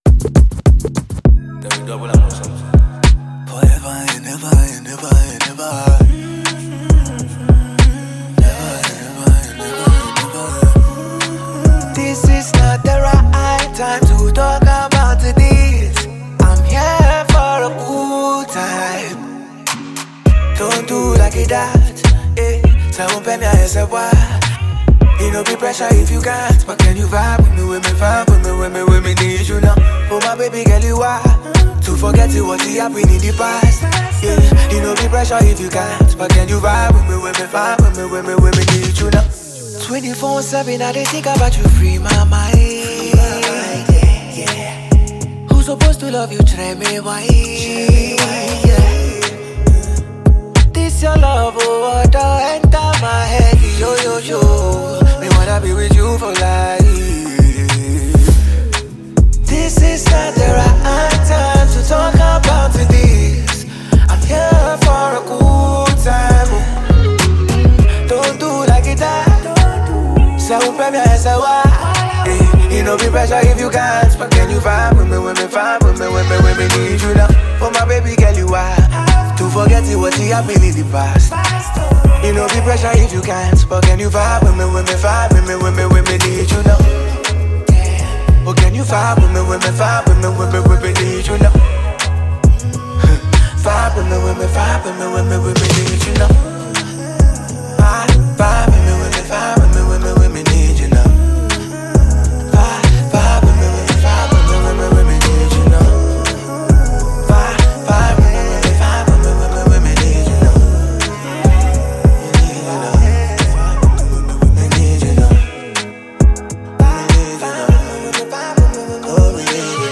with its catchy melodies and elegant production